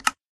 door_close.ogg